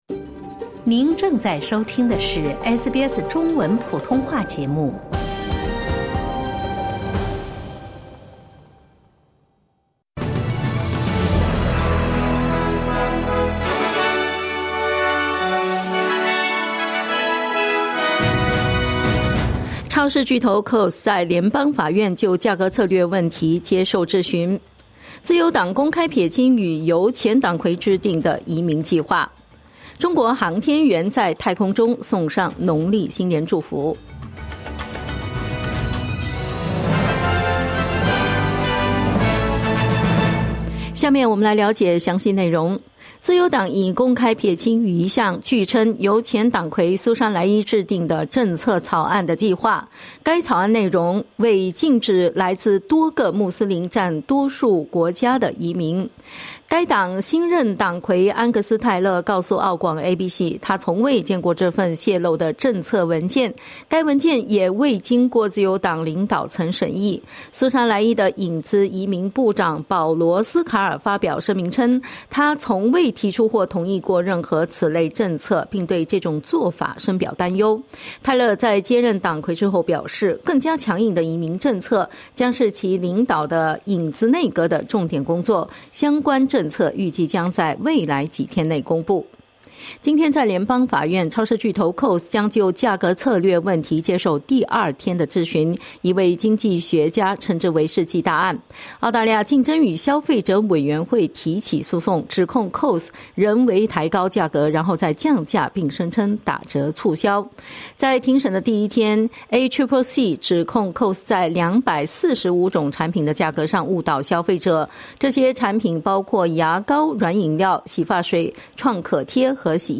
【SBS早新闻】超市巨头Coles接受质询